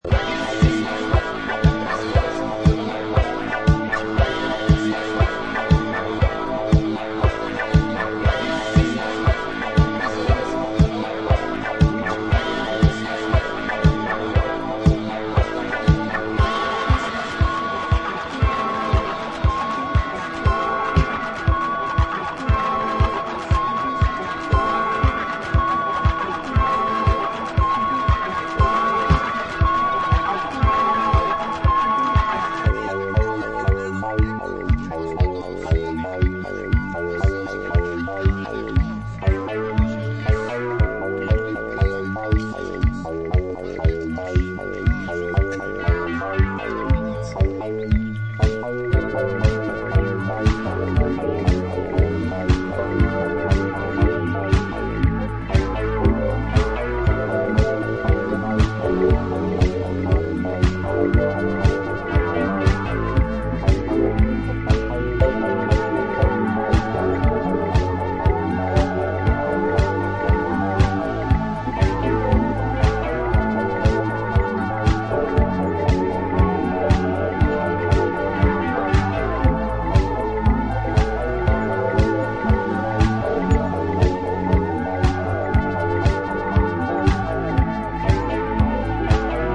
fantastic and experimental
60s-70s SF-style electronic sounds